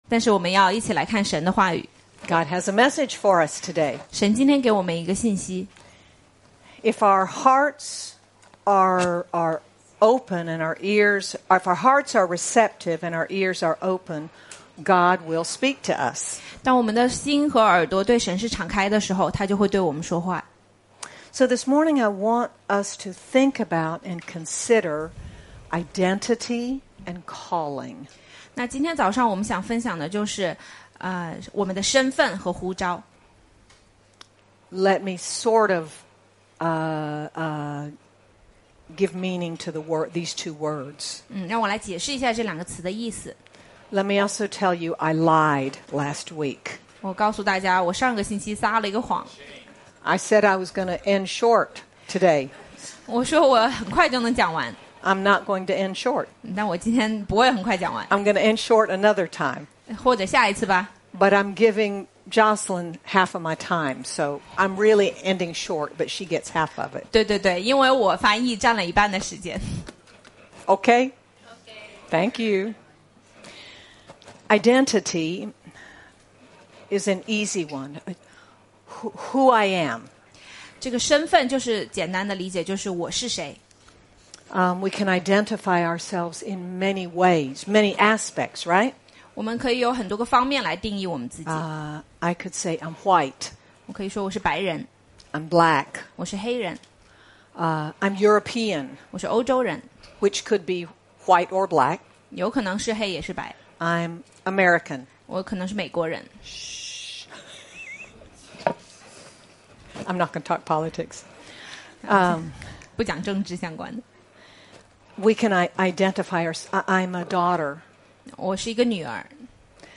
Moses and Gideon are examples to us in showing how our identity and calling are revealed in His Presence. Sermon by